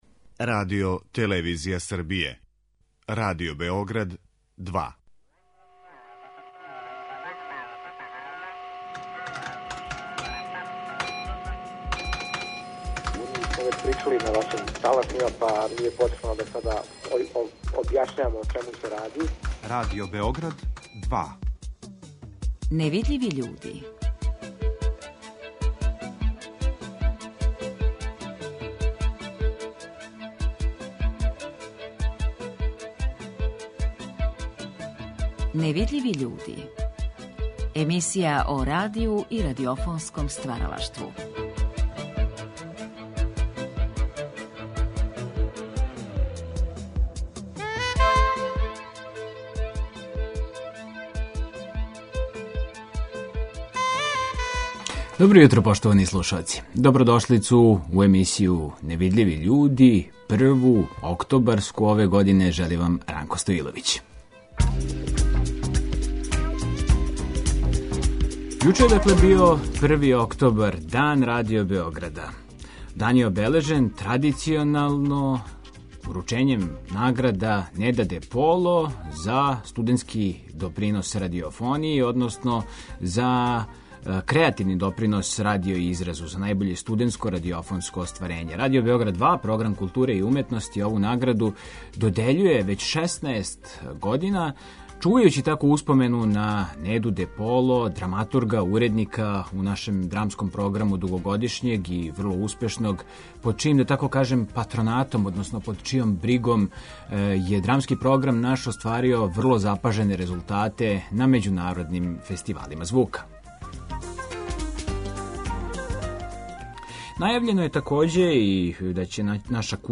Ови разговори вођени су за циклус емисија „Гост Другог програма" 1974. године.